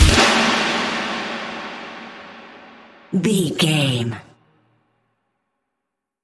Aeolian/Minor
E♭
drums
electric guitar
bass guitar
hard rock
lead guitar
aggressive
energetic
intense
nu metal
alternative metal